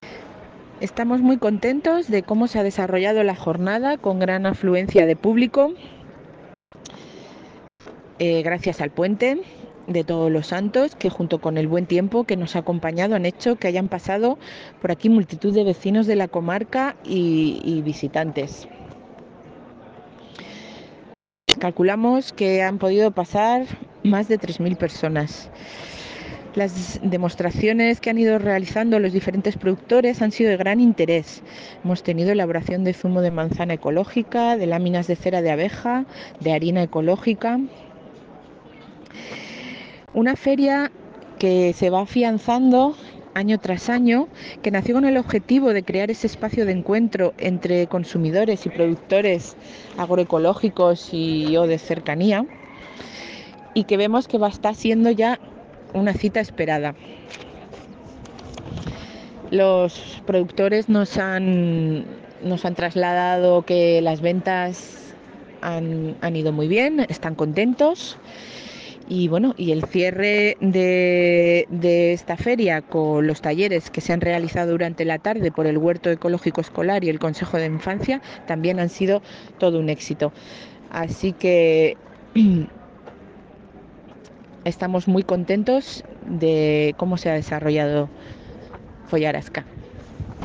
Declaraciones Susana Perez